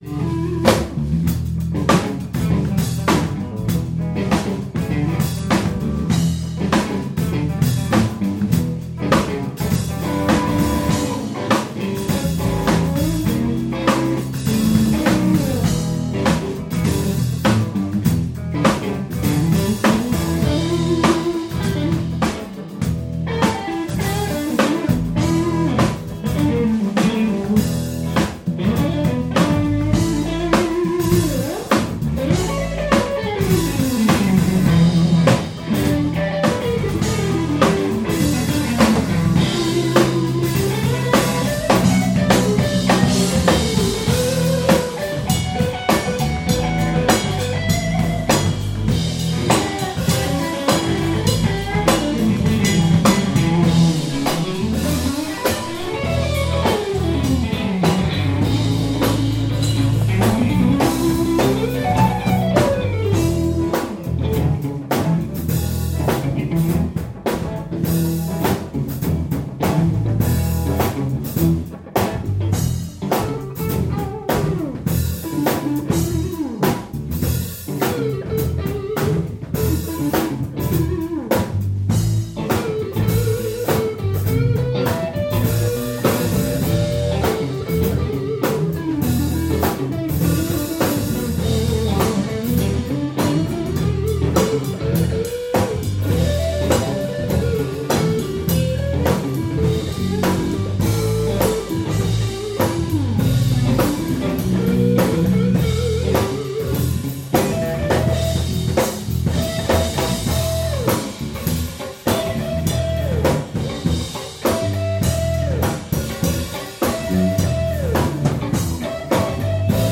Und wenn man es in der Eifel auf einer lauten Session einfach mal aufs Keyboard legt und mitlaufen lässt, dann klingt es
(Nichts nachbearbeitet.)
AhrdorfBlues.mp3